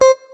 note8.ogg